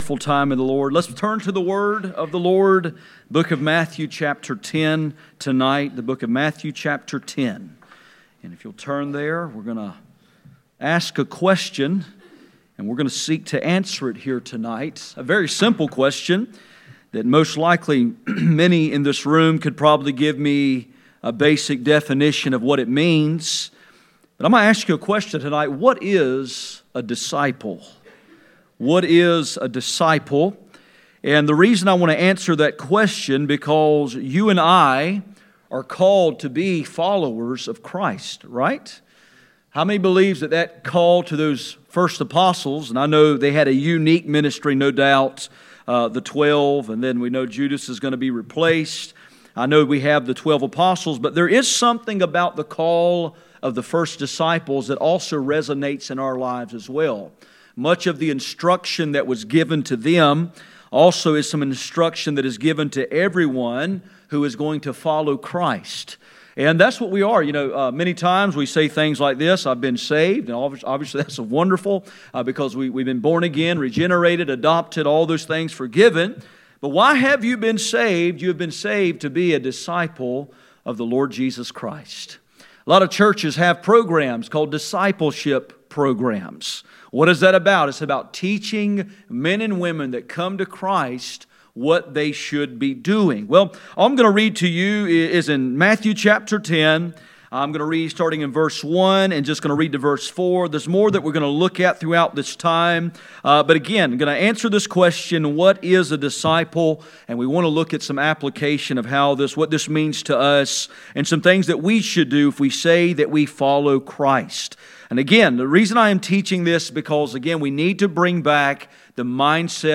None Passage: Matthew 10:1-4 Service Type: Sunday Evening %todo_render% « Living under the Sun